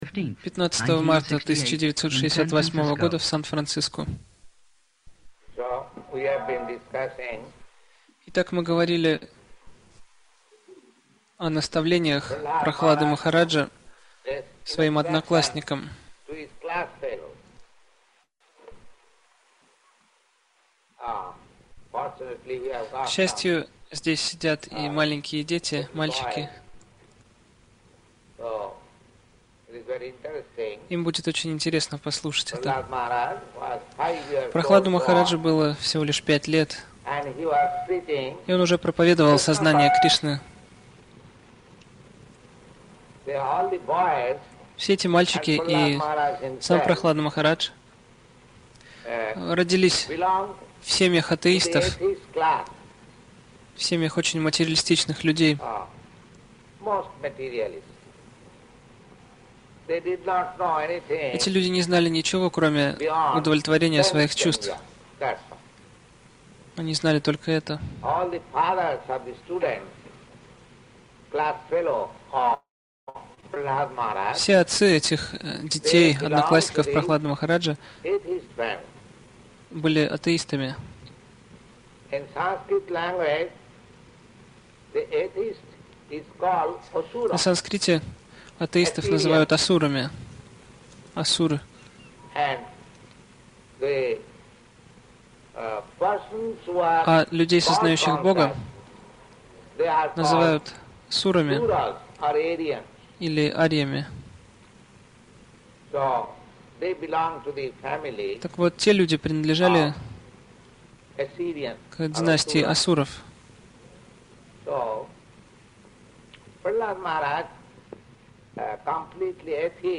обсуждение лекции